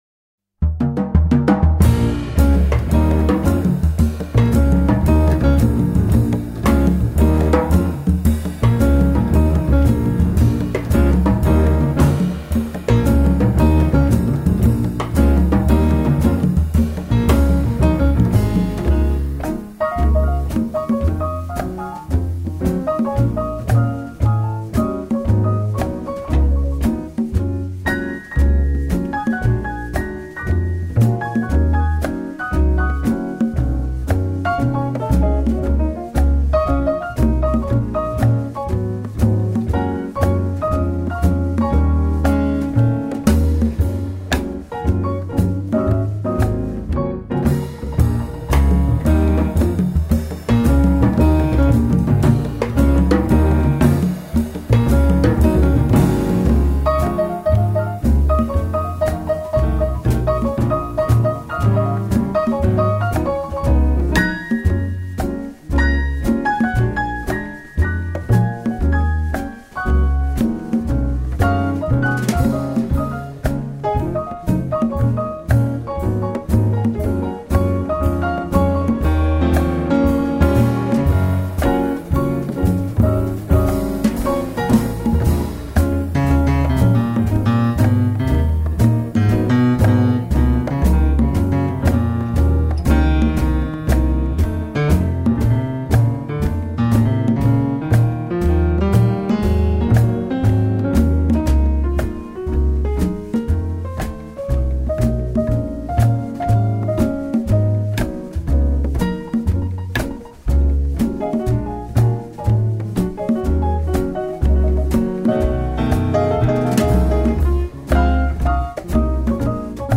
Take a listen, for example, to his interpretation of